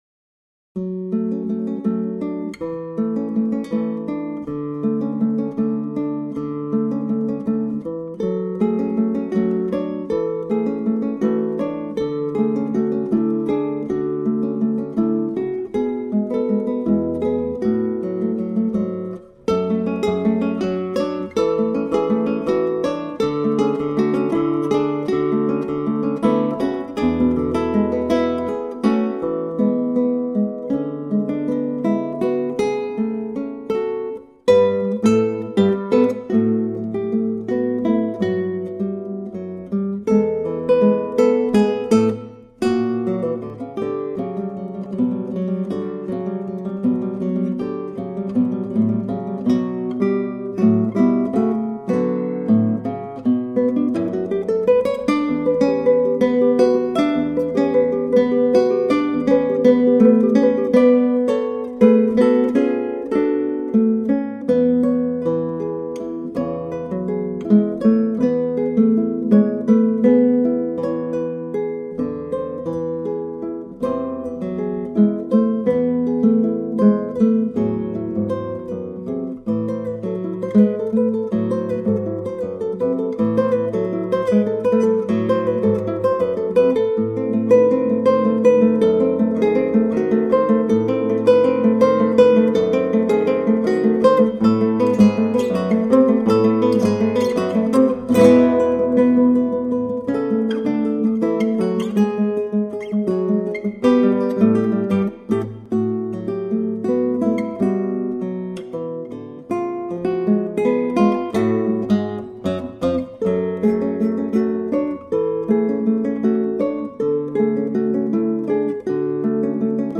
Both pieces were written for guitar duo.